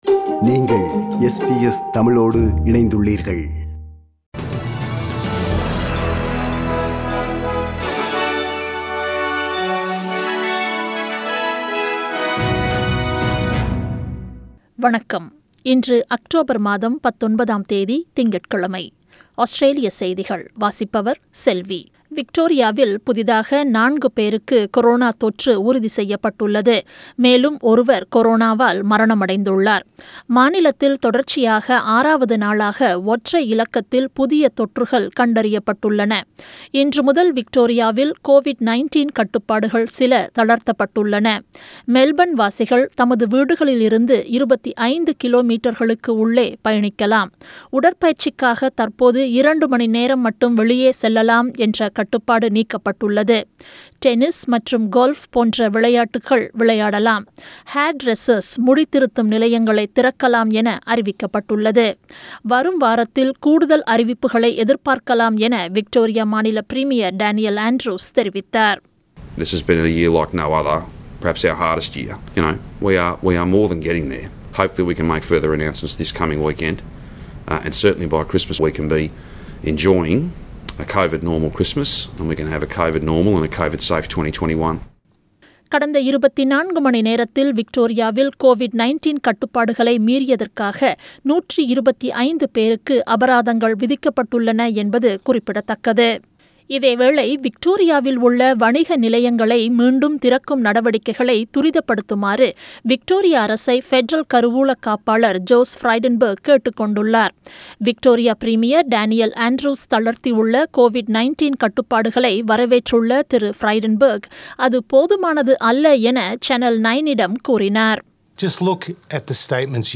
நமது SBS தமிழ் ஒலிபரப்பின் இன்றைய ( திங்கள் 19/10/2020) ஆஸ்திரேலியா குறித்த செய்திகள்.